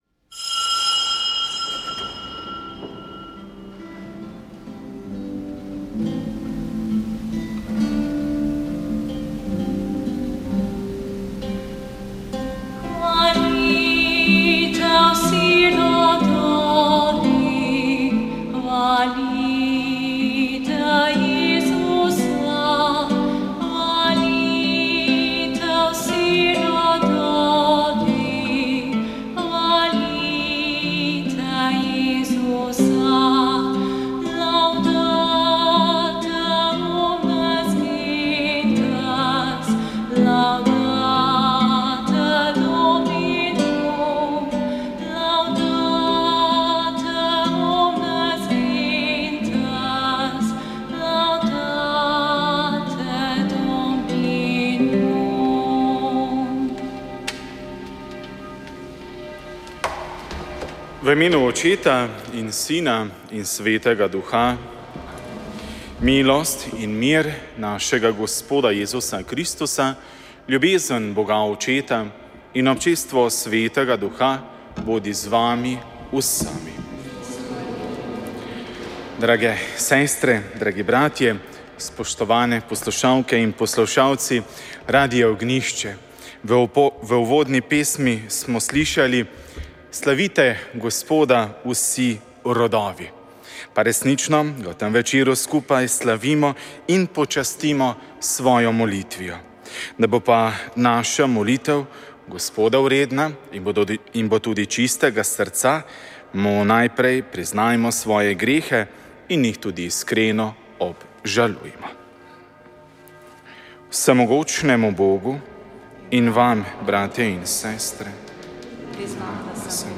Sveta maša
Sv. maša iz cerkve sv. Marka na Markovcu v Kopru 29. 12.